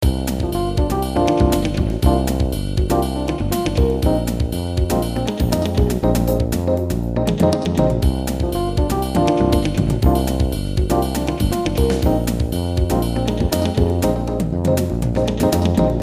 拉丁语介绍
描述：3个midi文件在logic中录制，钢琴、git和打击乐器。
Tag: 120 bpm Jazz Loops Groove Loops 2.70 MB wav Key : Unknown